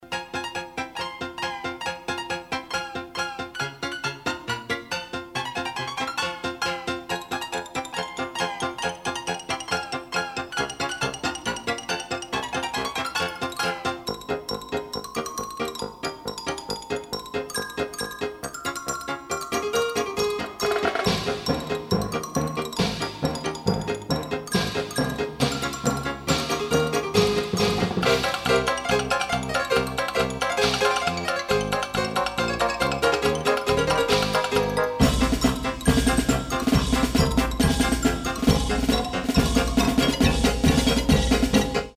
• Качество: 192, Stereo
веселые
инструментальные
смешные